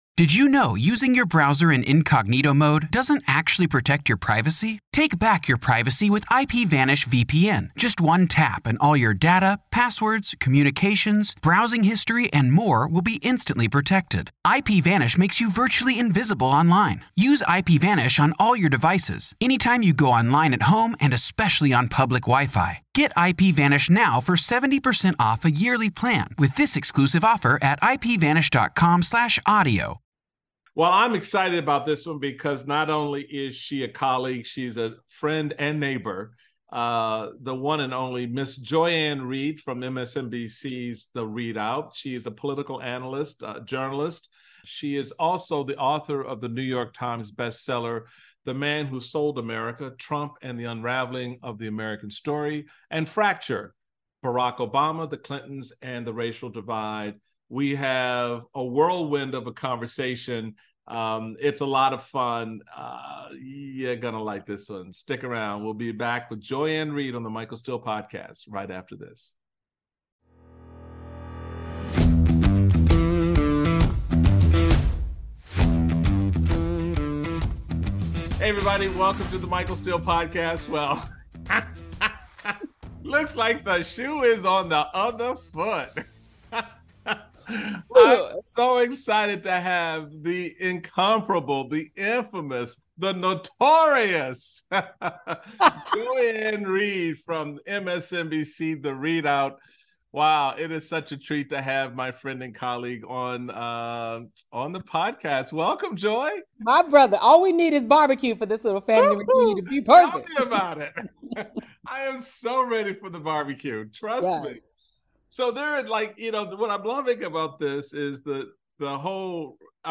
Joy-Ann Reid joins Michael Steele to talk political journalism and the strengths and weaknesses of the two political parties.